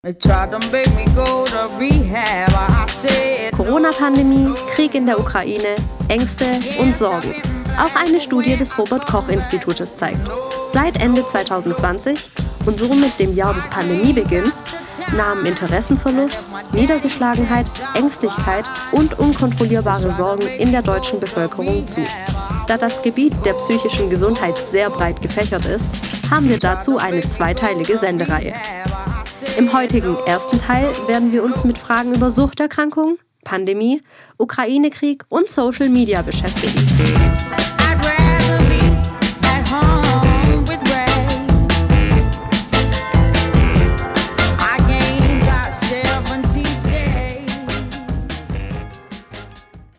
Ein Patient, der selbst in Therapie geht uns seinen Namen nicht im Radio hören will, erklärt, wie er die Pandemiejahre empfunden und was der Ukrainekrieg in ihm ausgelöst hat.